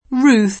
vai all'elenco alfabetico delle voci ingrandisci il carattere 100% rimpicciolisci il carattere stampa invia tramite posta elettronica codividi su Facebook Ruth [ rut ; lat. rut ; ingl. r 2 u T ; ted. r 2 ut ] pers. f. bibl.